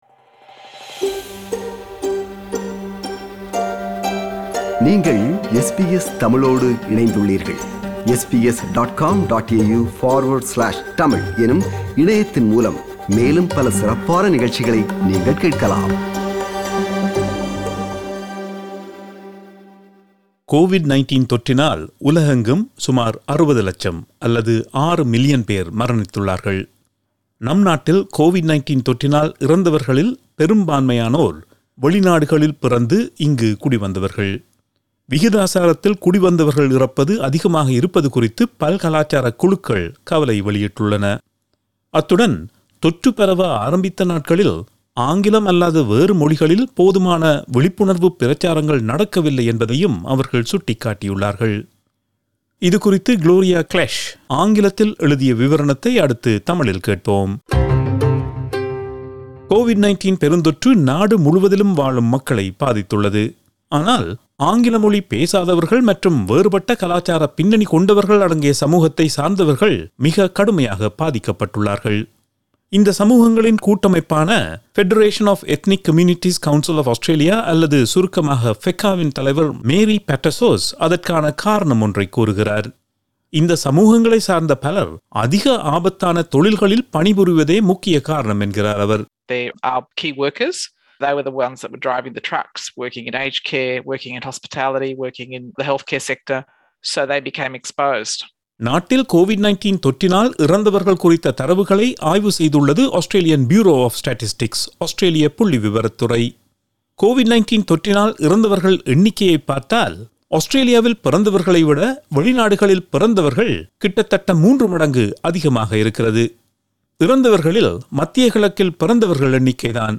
reports in Tamil